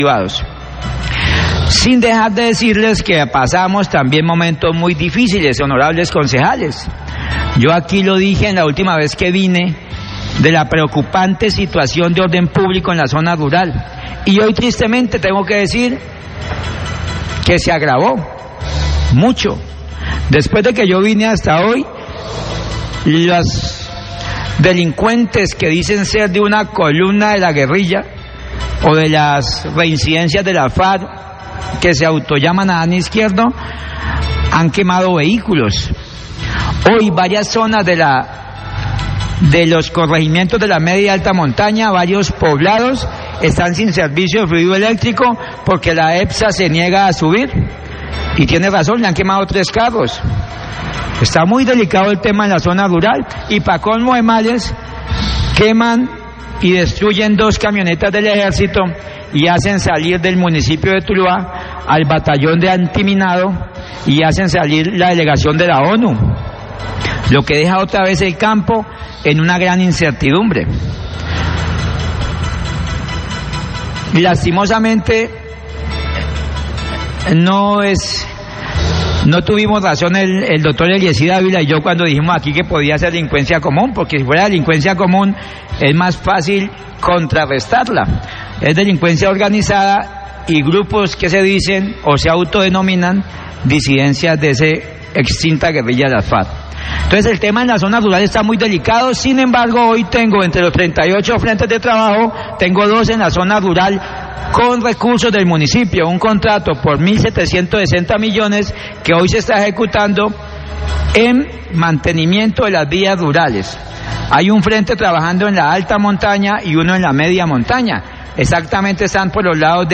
Alcalde de Tuluá habla sobre la violencia que vive la zona rural y la necesidad de seguir trabajando por ella, Guasca Fm, 1210pm
Radio